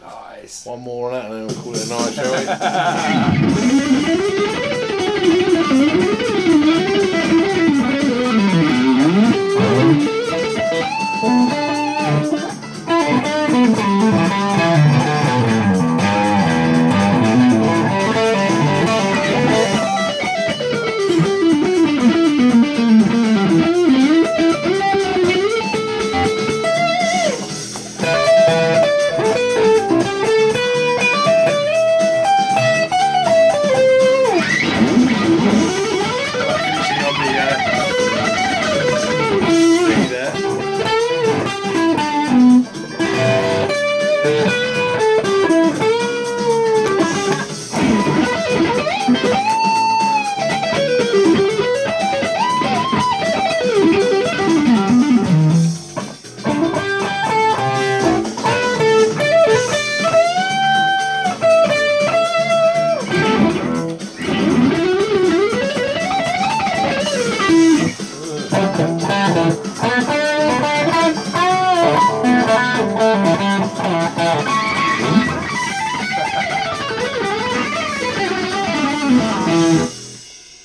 Finally, we have a go at improvising over some backing tracks.
You can hear a clip of the final round of this improvisation session here.  In this clip over a Blues in E